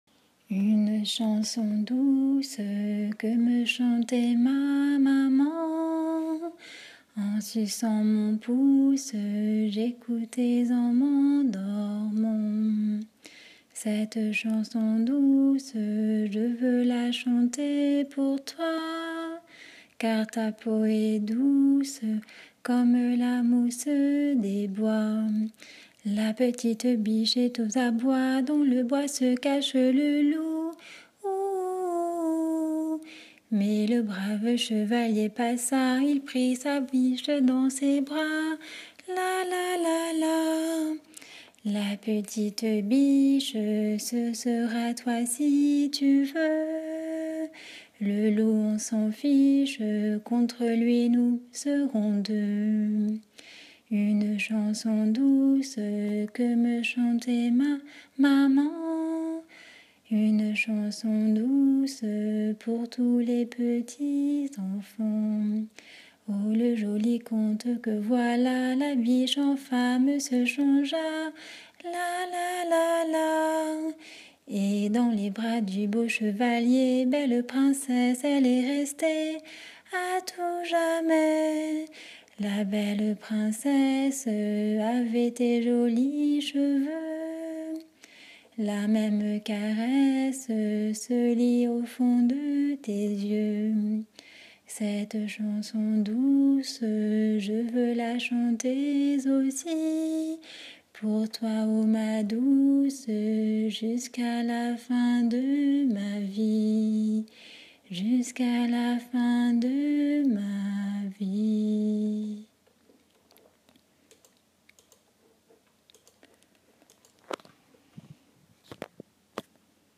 Comptines
Une chanson toute douce à écouter en suçant son pouce